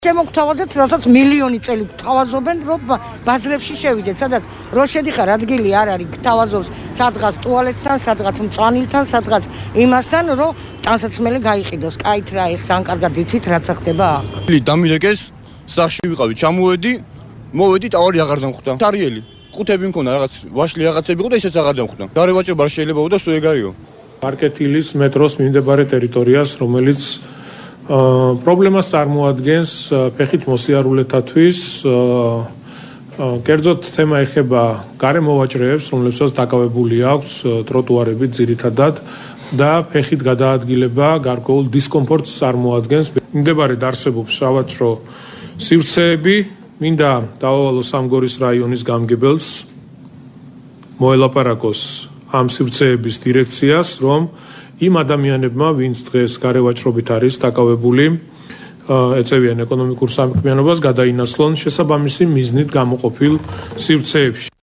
გარემოვაჭრეების და კახა კალაძის ხმა